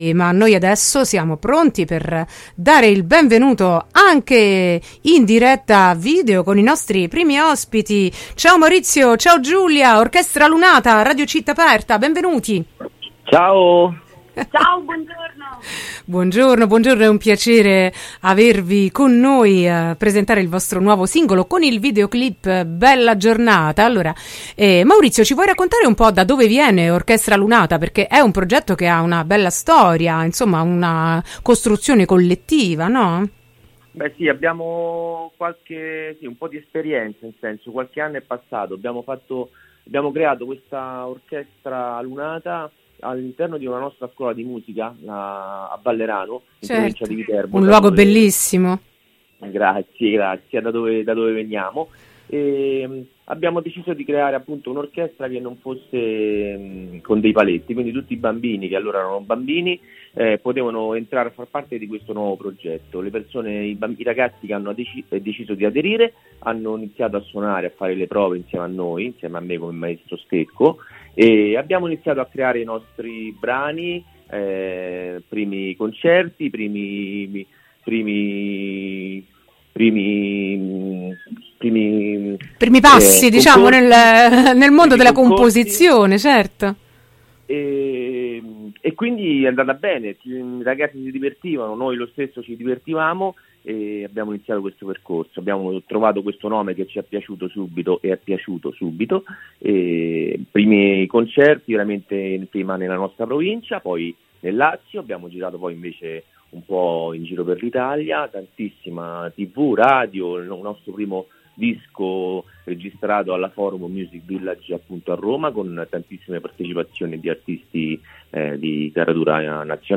intervista-orchestralunata-22-4-22.mp3